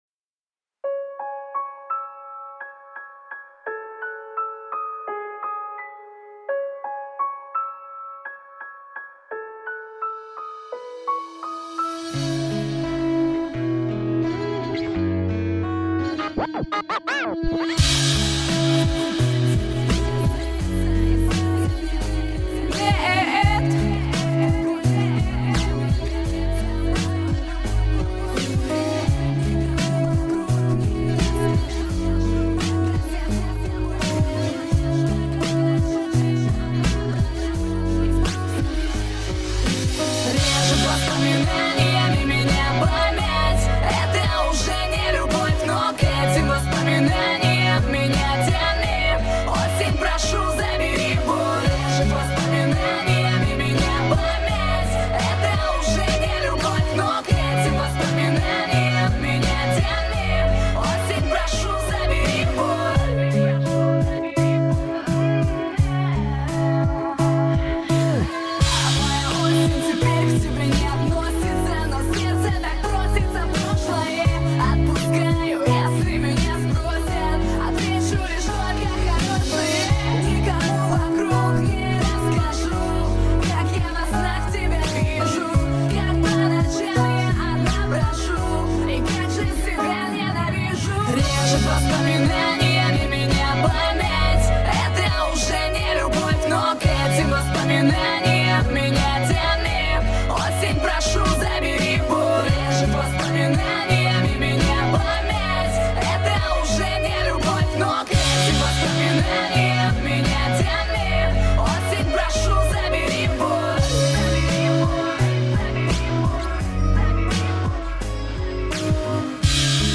минусовка версия 48975